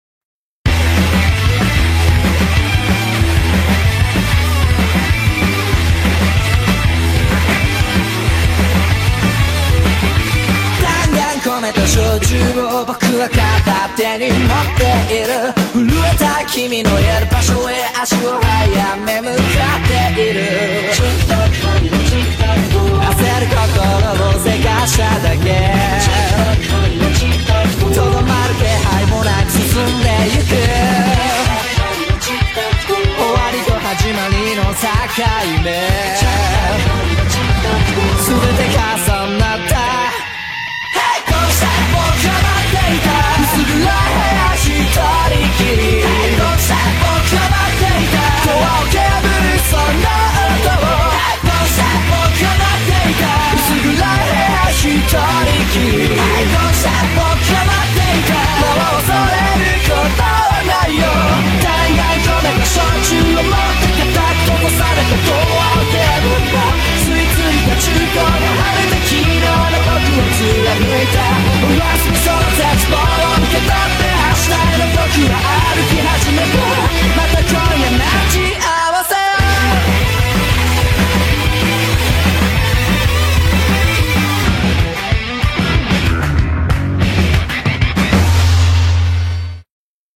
BPM189